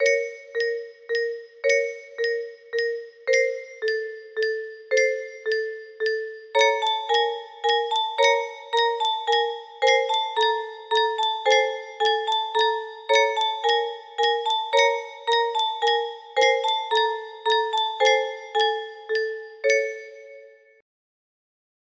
a new acquaintance music box melody